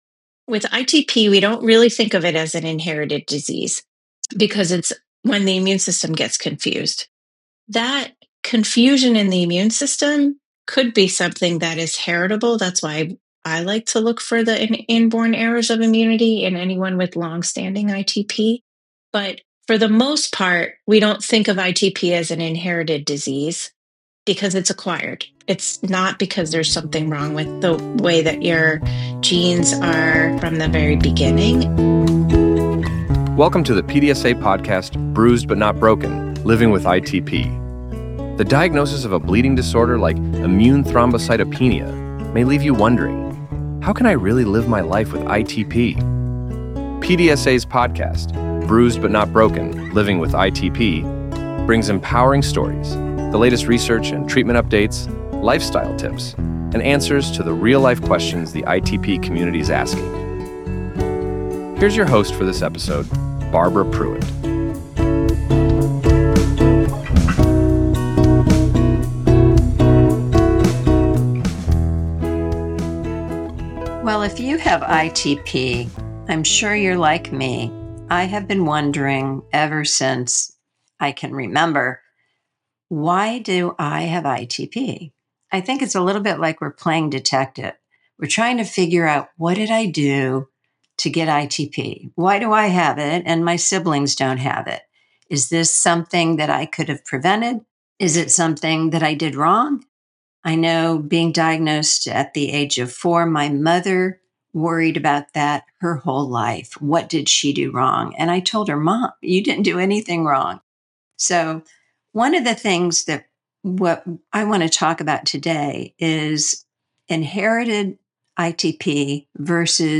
Whether you're newly diagnosed or have lived with ITP for years, this conversation offers eye-opening insights, practical takeaways, and real hope for more personalized care in the future.